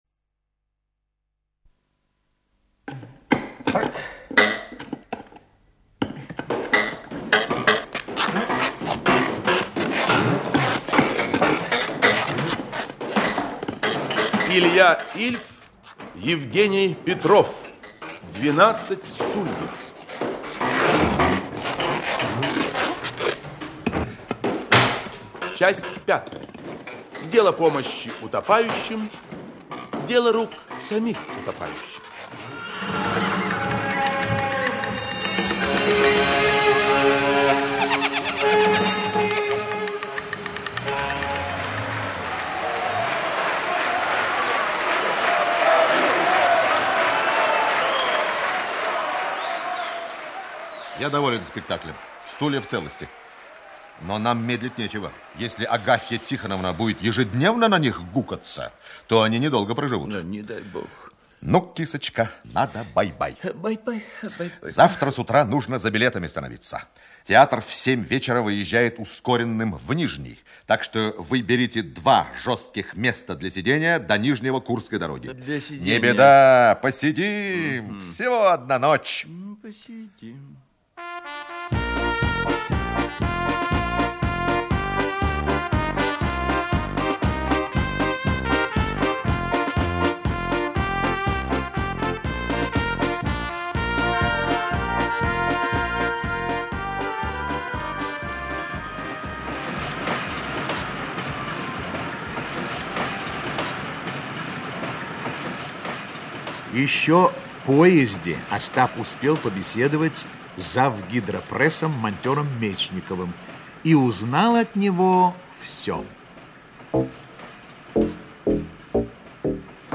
Аудиокнига 12 стульев (спектакль) Часть 5-я. Дело помощи утопающим – дело рук самих утопающих | Библиотека аудиокниг
Aудиокнига 12 стульев (спектакль) Часть 5-я. Дело помощи утопающим – дело рук самих утопающих Автор Илья Ильф Читает аудиокнигу Актерский коллектив.